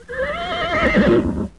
Horse Sound Effect
horse-2.mp3